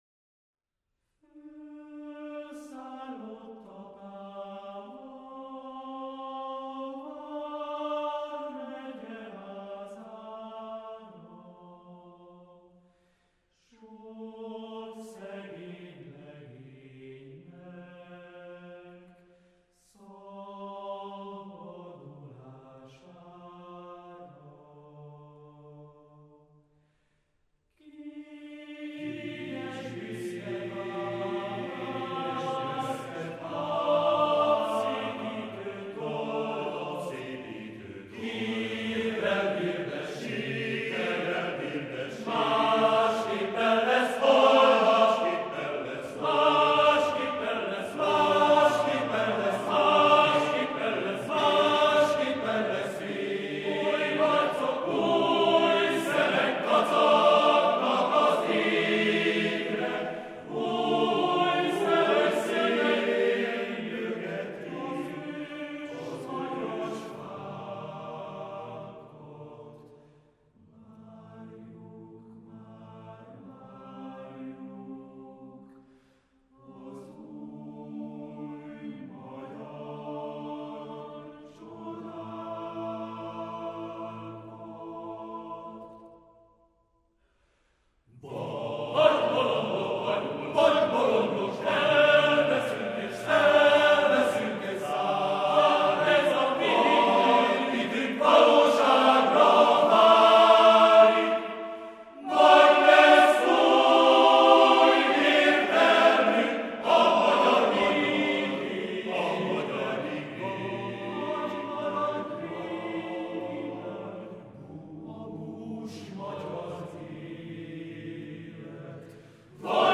男声合唱のための曲を作りました。